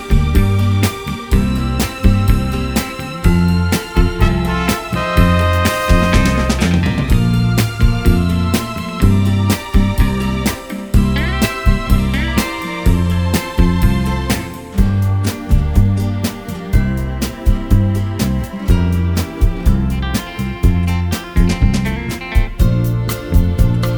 no Backing Vocals Crooners 2:58 Buy £1.50